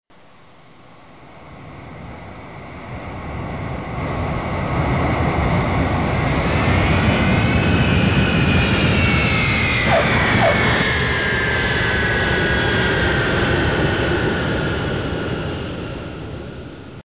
飛機降落
aircraft_landing.mp3